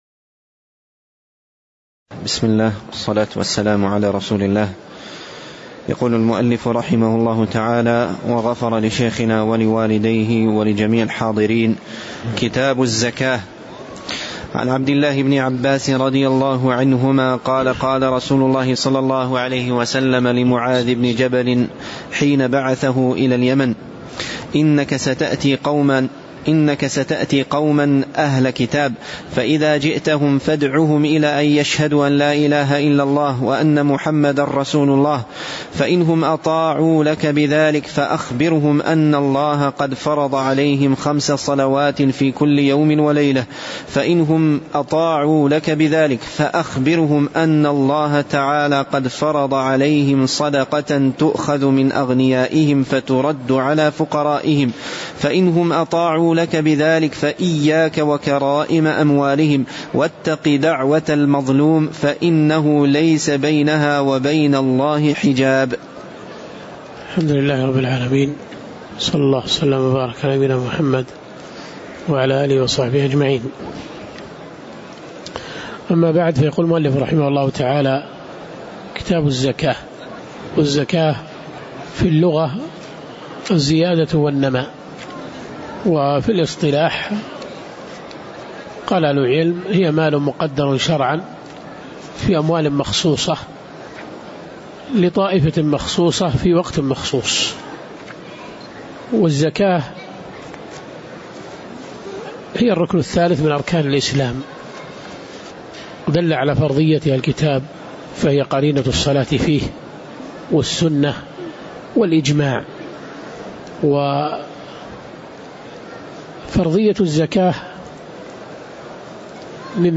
تاريخ النشر ٣ ربيع الثاني ١٤٣٨ هـ المكان: المسجد النبوي الشيخ